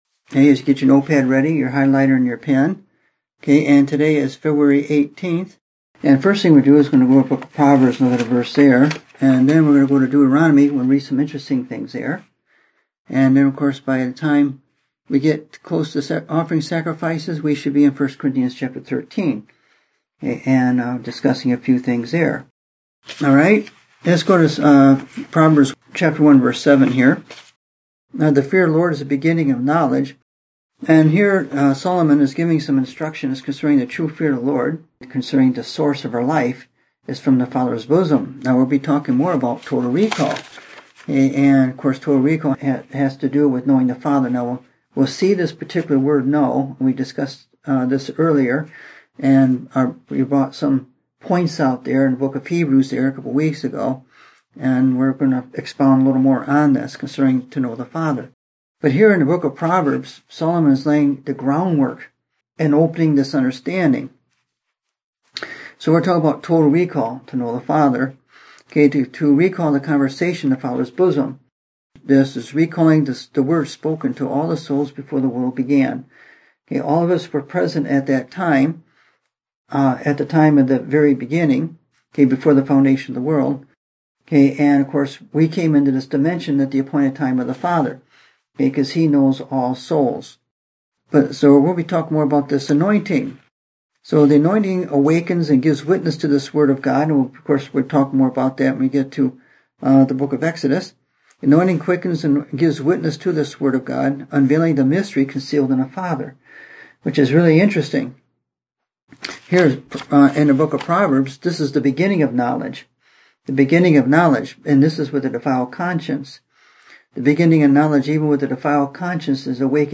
Listen to the message I preached February 18, 2018: Are You Denying the Life of Christ?